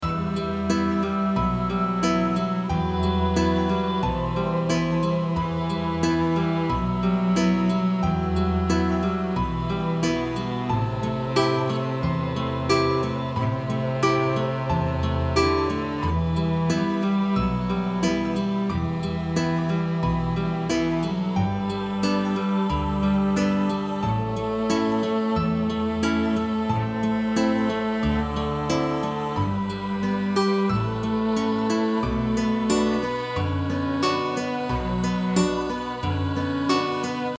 wind and deep sounds Mood: Ambient Instruments
Boy soprano Genre: World music Composer/Artist
Loop Underscore